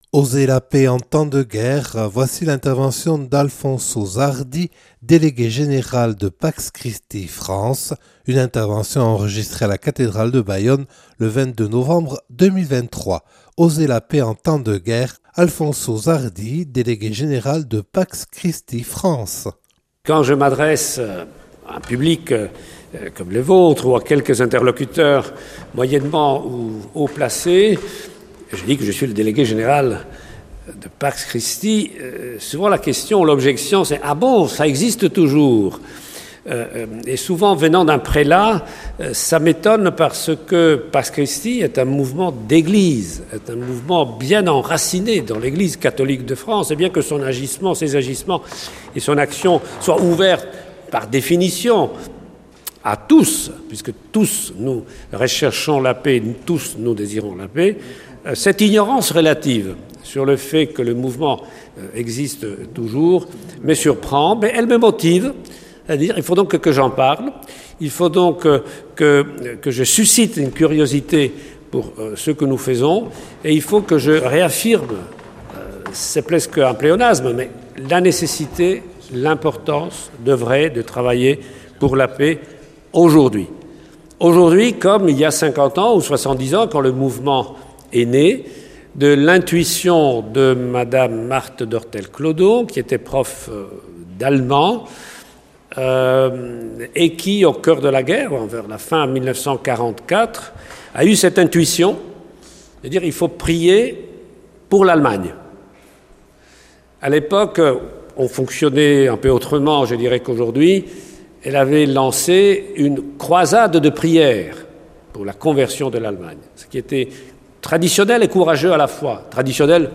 (Enregistré le 22 novembre 2023 à la cathédrale de Bayonne).